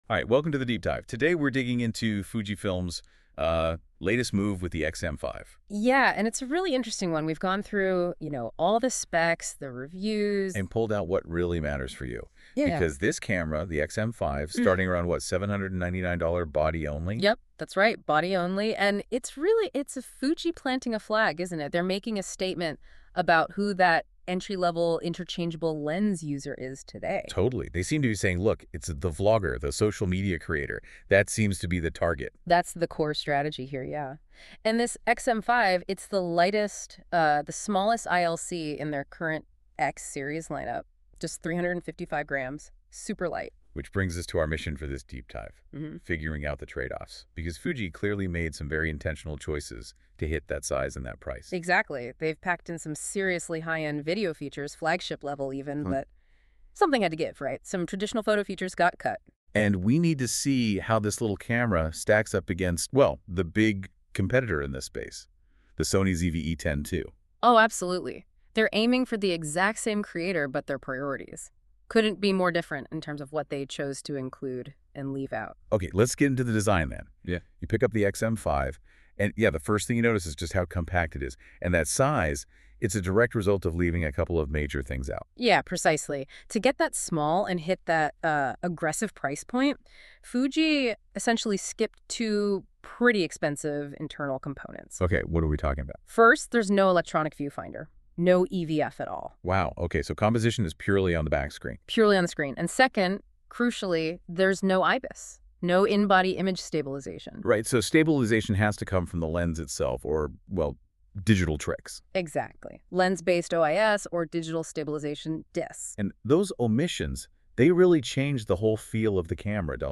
5 Surprising Truths About the Fujifilm X-M5 That No One is Talking About Download the audio: MP3 | Short note: This is an AI podcast about Fujifilm X-M5 and it's features.